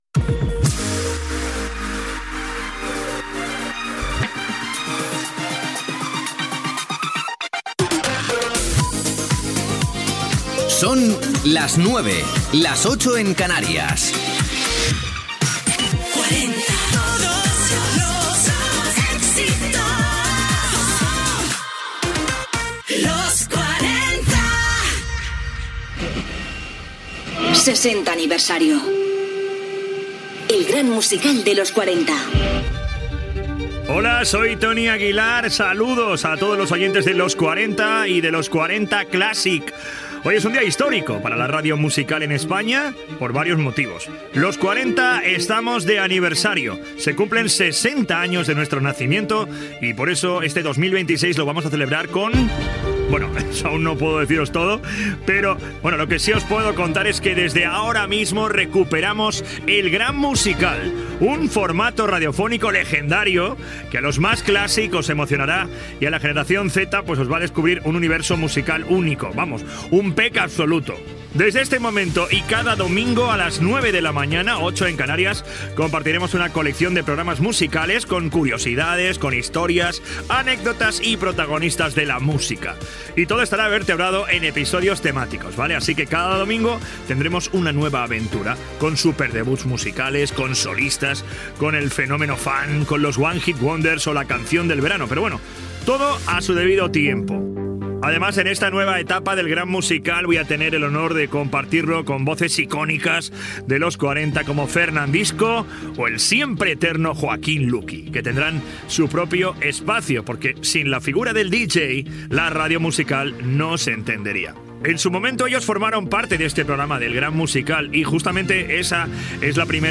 Hora, indicatiu de la cadena. Primera edició del retorn del programa per celebrar el 60è aniversari de la fórmula "Los 40 principales".
Musical